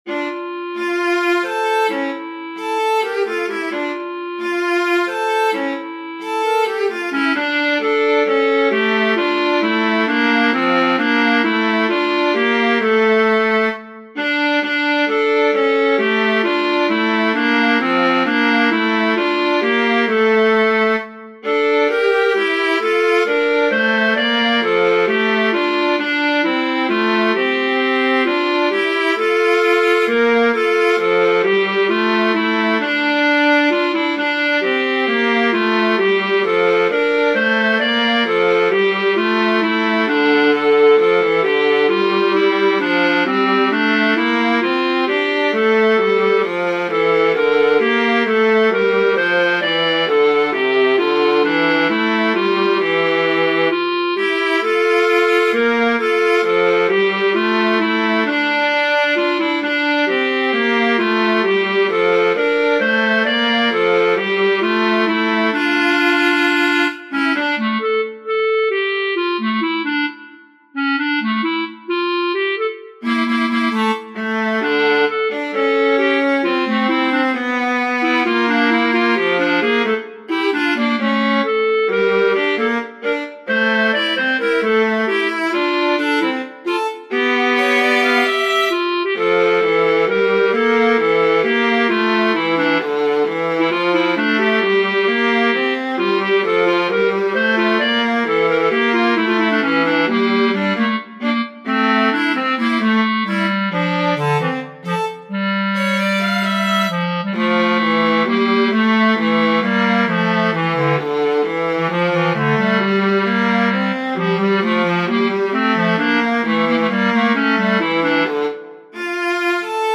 Clarinet, Viola